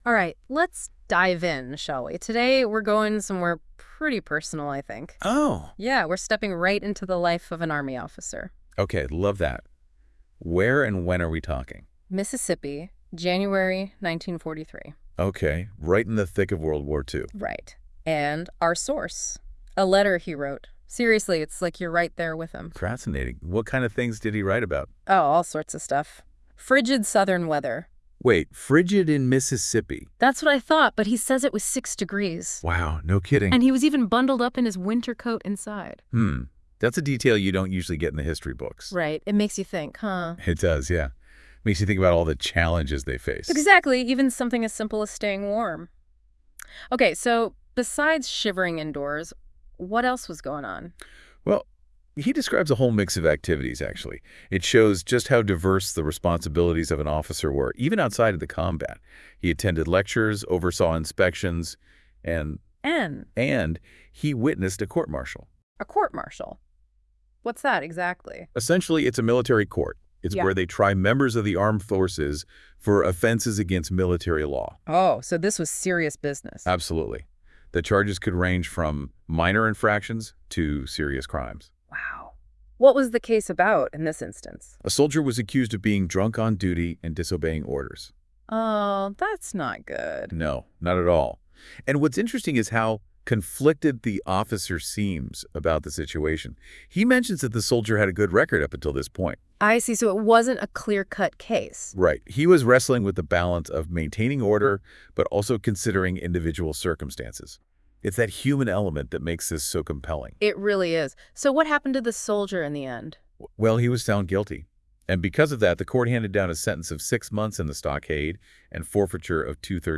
For a different way to take in these letters, listen to this AI-generated podcast of the Jan 12, 1943 letter.